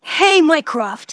synthetic-wakewords
ovos-tts-plugin-deepponies_Spike_en.wav